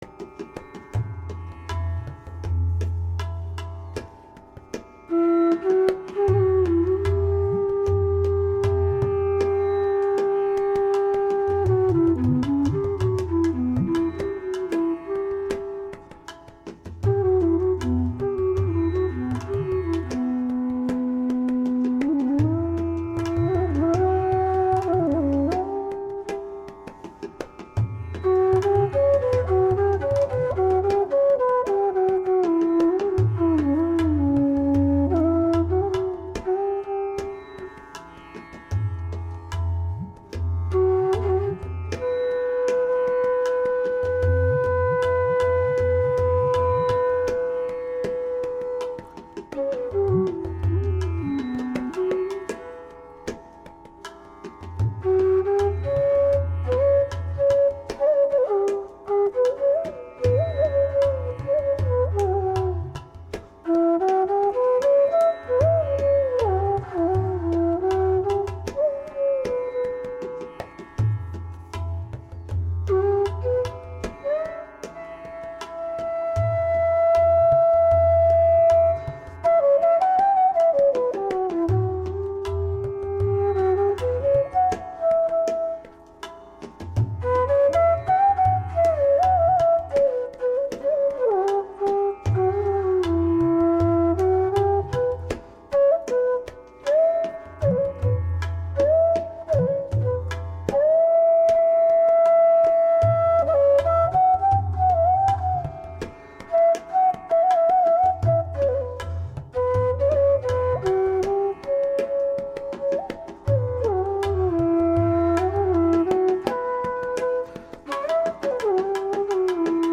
Contemplative bansuri melodies
tabla
Genre: North Indian Classical.
Rupak Tal (7)   14:08